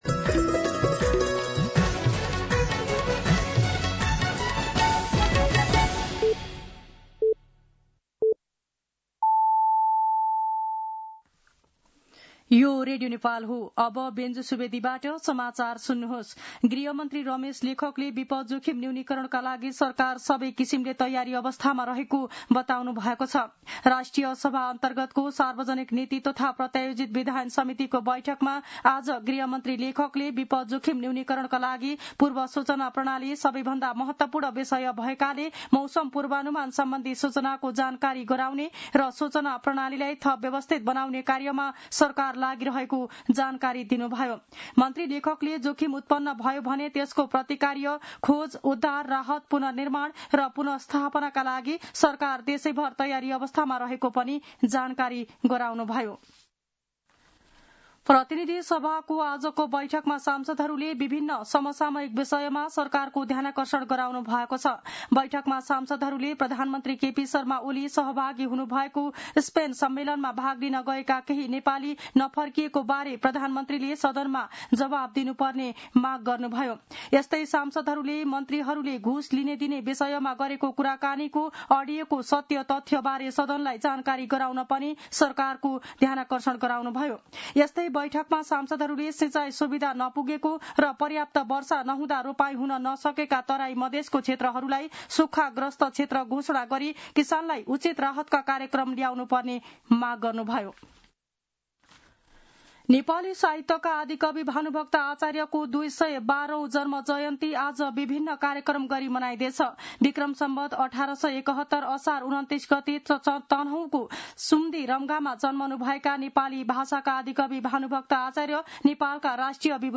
दिउँसो ४ बजेको नेपाली समाचार : २९ असार , २०८२